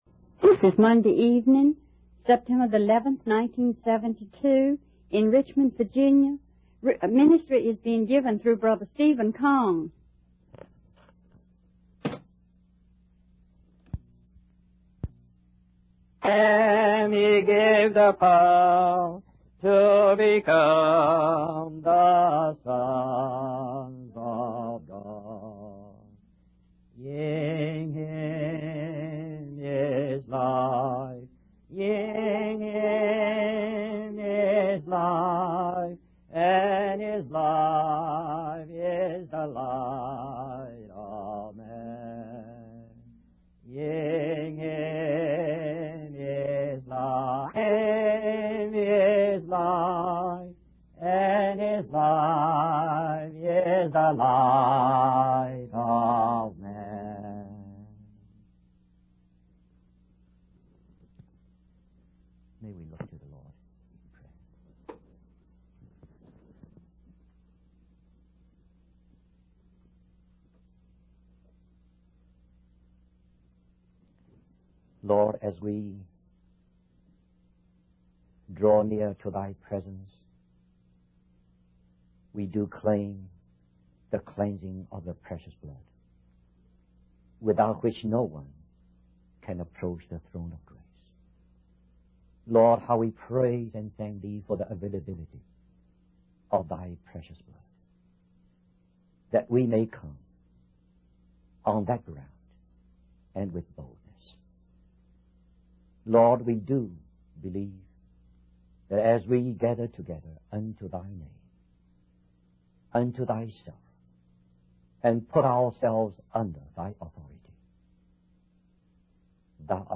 In this sermon, the preacher focuses on the fourth sign mentioned in the Gospel of John, which is the multiplication of the loaves and fishes.